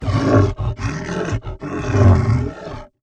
MONSTERS_CREATURES
MONSTER_Exhausted_03_mono.wav